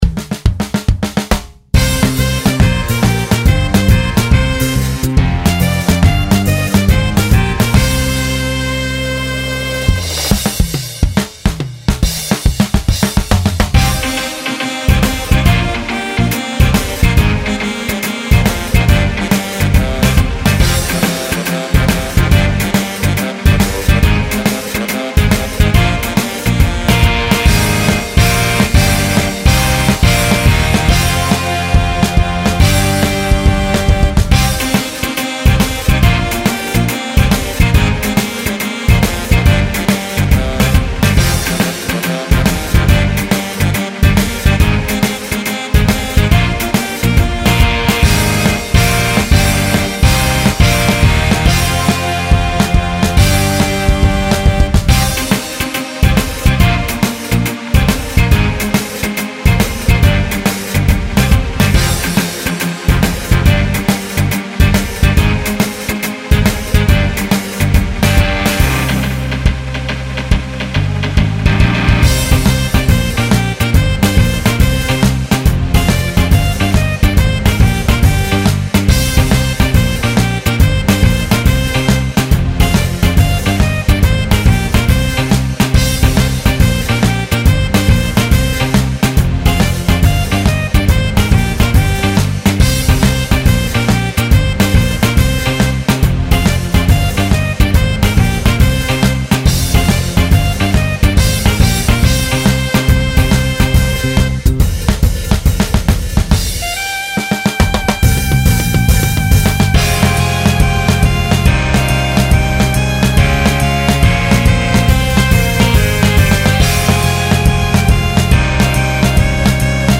ポップな音頭